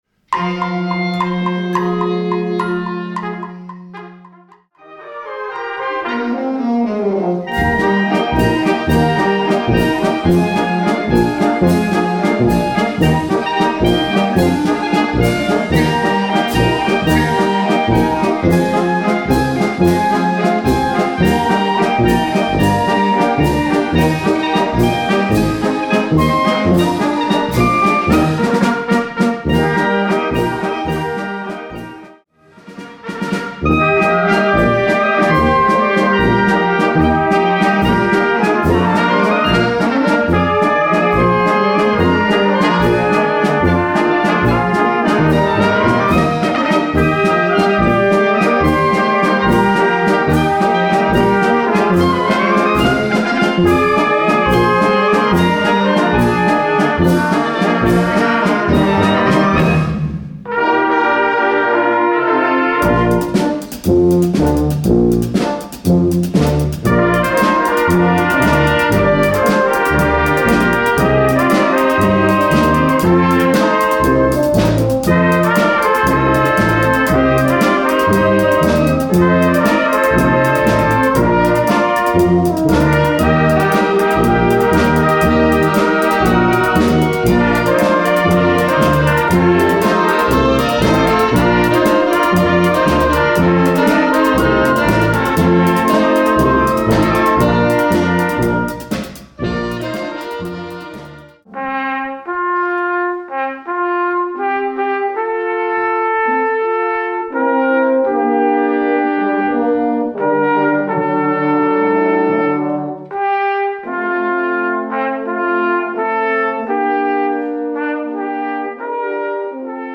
Winter: ein Moll-Walzer beschließt den Jahresreigen.